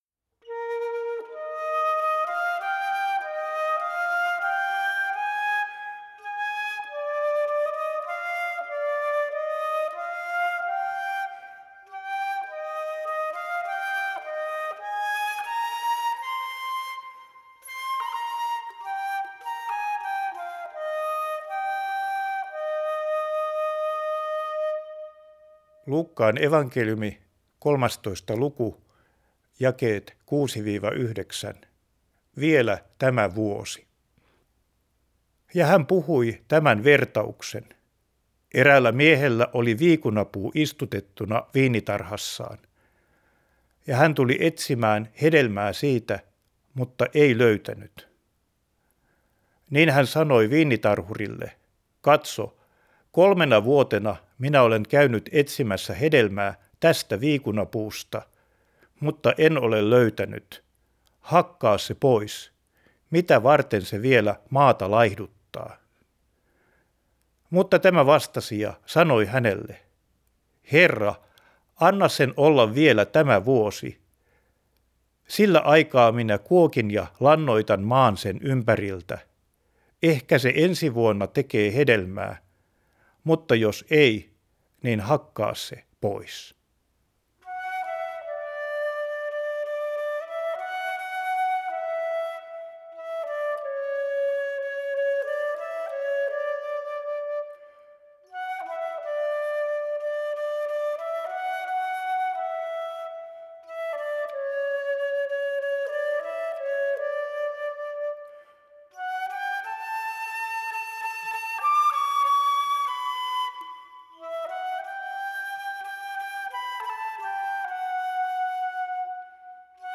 Luuk. 13:6-9 Vielä tämä vuosi Raamatunlukua. Luukkaan evankeliumi luku 13, jakeet 6-9.